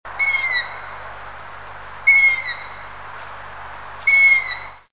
Sa vie est exclusivement nocturne avec son ululement régulier et grave. .
- Le chant du Hibou moyen duc
hibou_moyen duc.mp3